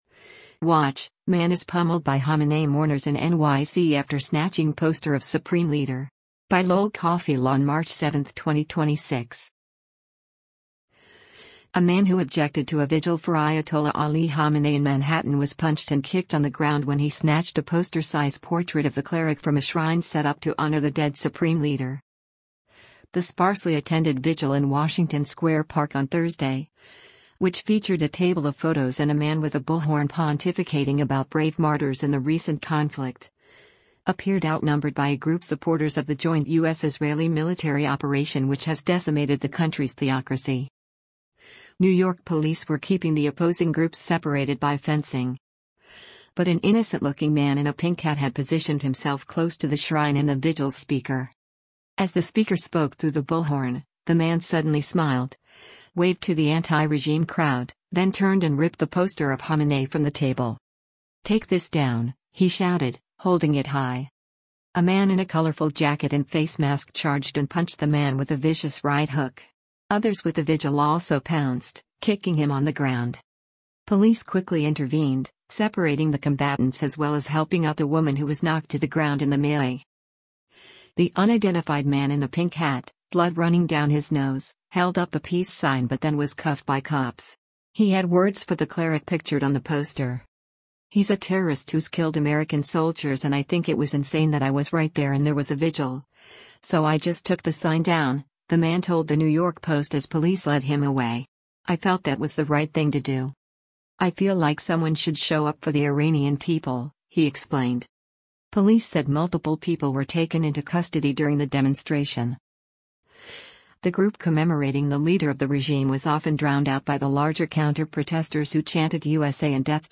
The sparsely attended vigil in Washington Square Park on Thursday, which featured a table of photos and a man with a bullhorn pontificating about “brave martyrs” in the recent conflict, appeared outnumbered by a group supporters of the joint U.S.-Israeli military operation which has decimated the country’s theocracy.
“Take this down,” he shouted, holding it high.
The group commemorating the leader of the regime was often drowned out by the larger counter-protestors who chanted “USA” and “death to terrorists.”